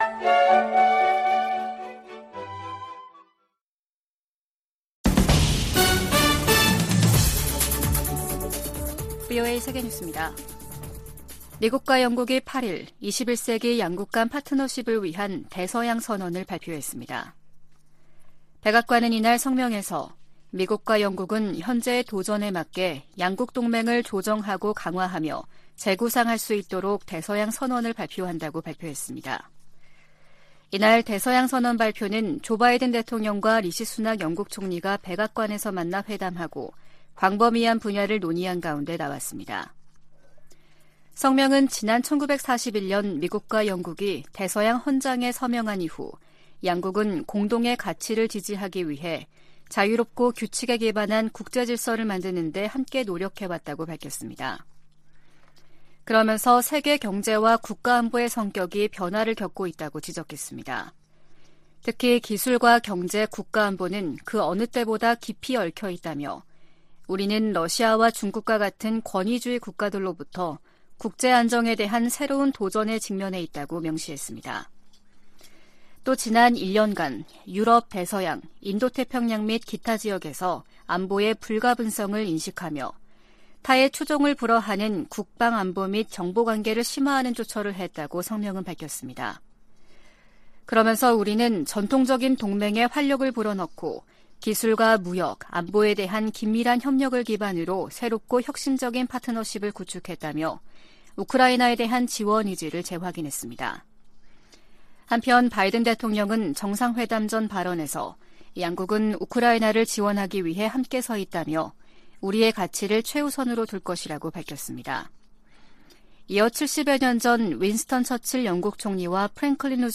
VOA 한국어 아침 뉴스 프로그램 '워싱턴 뉴스 광장' 2023년 6월 9일 방송입니다. 미국은 국제원자력기구 이사회에서 북한의 전례 없는 미사일 발사를 거론하며 도발적 행동에 결과가 따를 것이라고 경고했습니다. 한국의 윤석열 정부는 개정된 안보전략에서 '종전선언'을 빼고 '북 핵 최우선 위협'을 명시했습니다. 백악관의 커트 캠벨 인도태평양조정관은 북한 문제를 중국과의 주요 협력 대상 중 하나로 꼽았습니다.